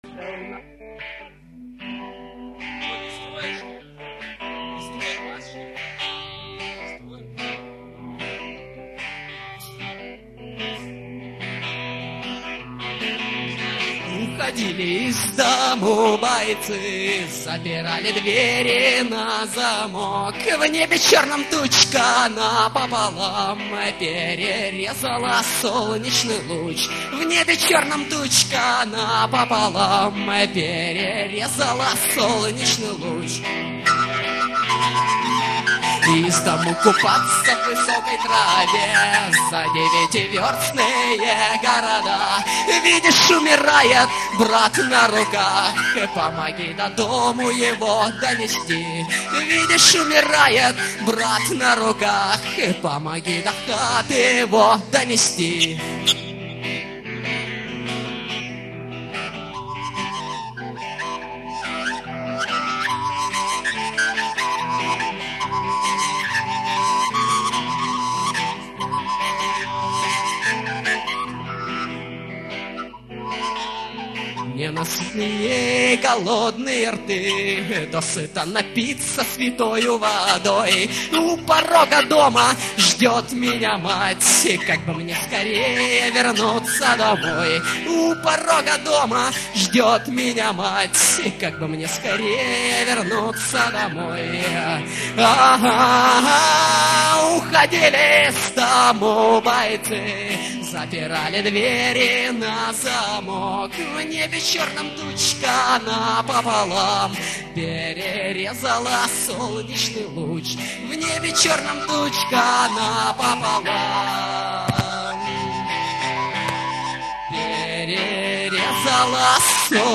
Концерт в Актюбинске . 1. 2. 3. 4. 5.